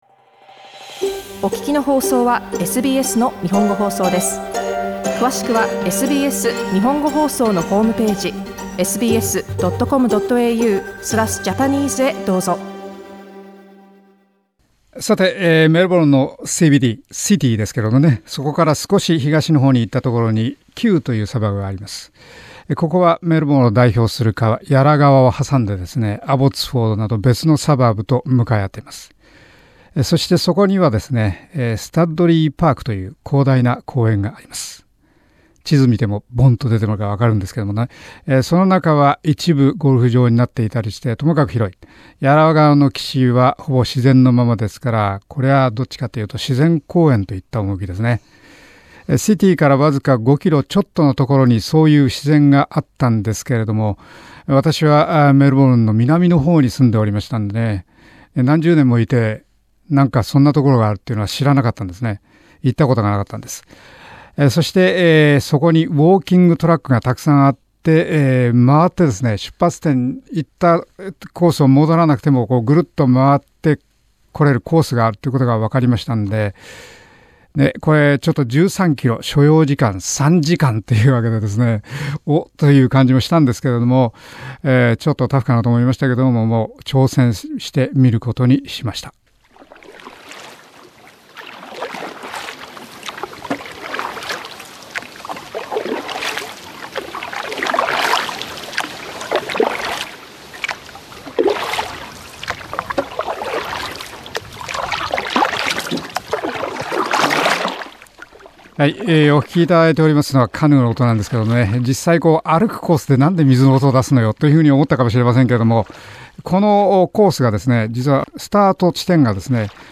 2013年５月の放送の同録です。